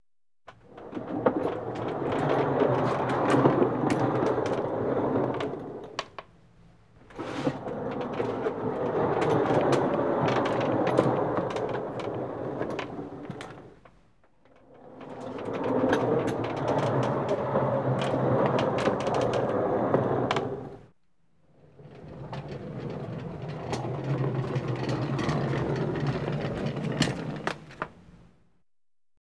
Тяжелую тележку с камнями двигают или толкают